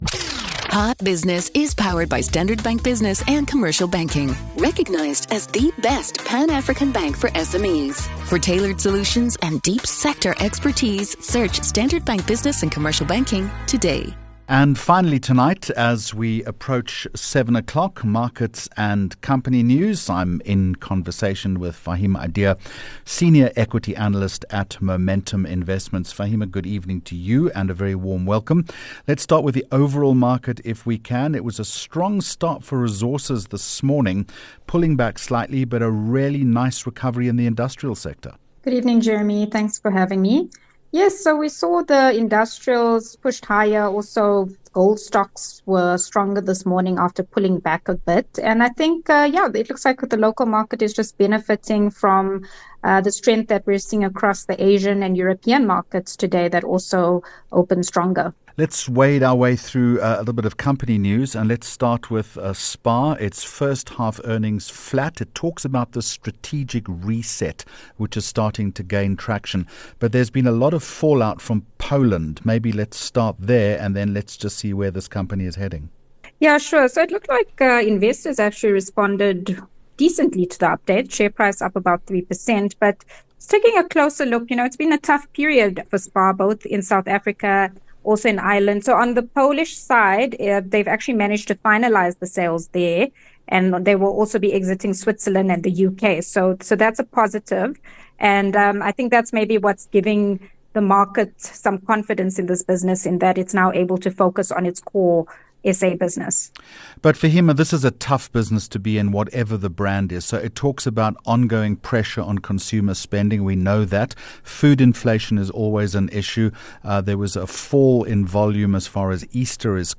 HOT Business with Jeremy Maggs, powered by Standard Bank 4 Jun Hot Business Interview